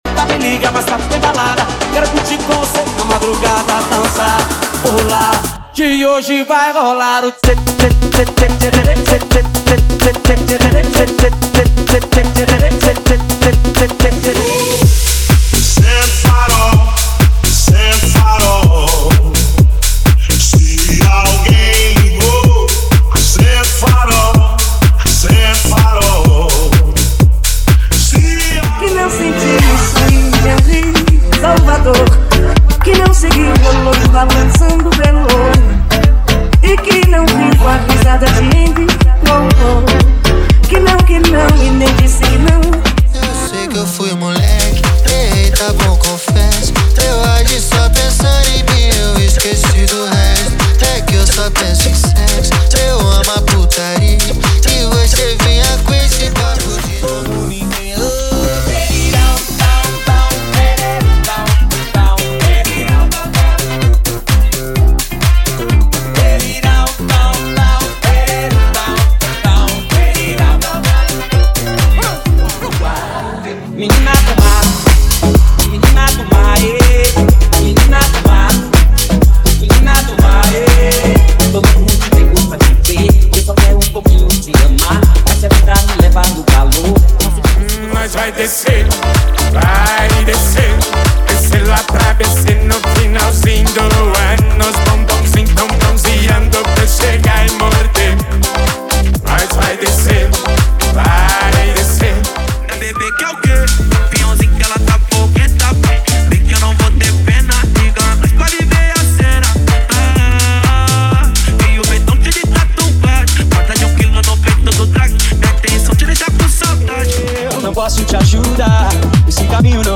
MEGA FUNK
– Sem Vinhetas